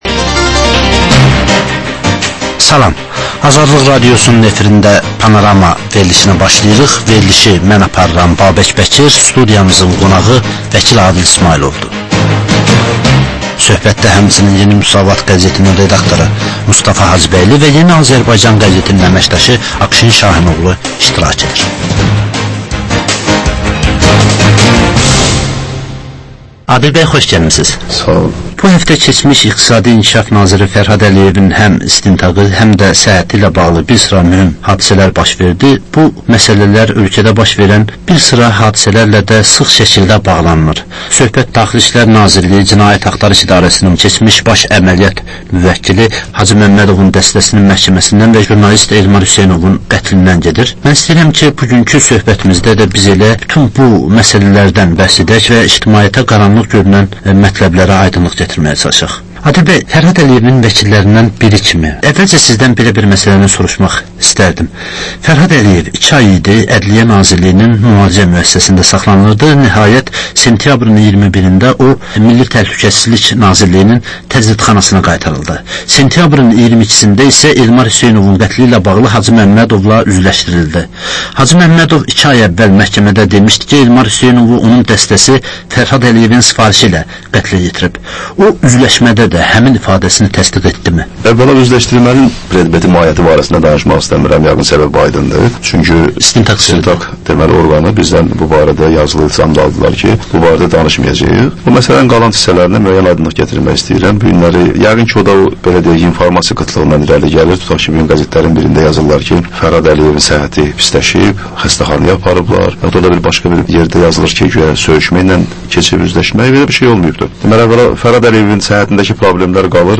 Jurnalistlər və həftənin xəbər adamıyla aktual mövzunun müzakirəsi